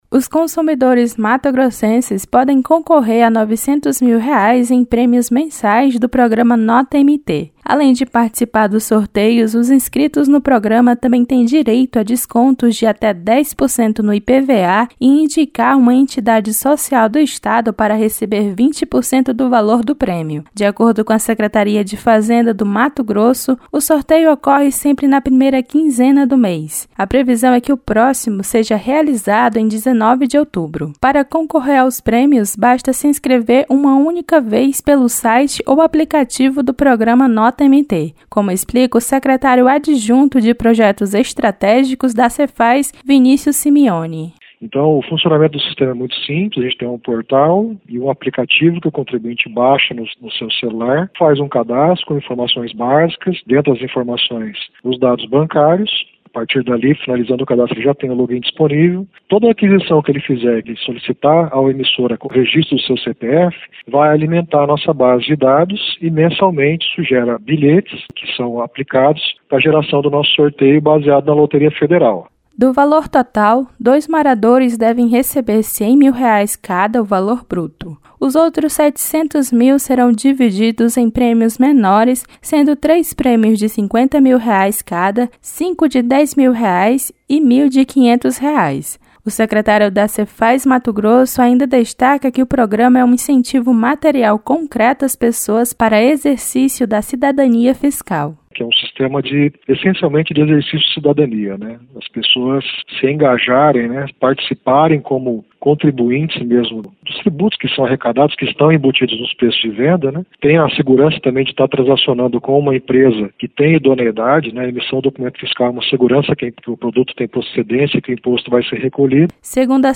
Para concorrer aos prêmios basta se inscrever uma única vez pelo site ou aplicativo do Programa Nota MT, como explica o secretário adjunto de projetos estratégicos da Sefaz, Vinícius Simioni.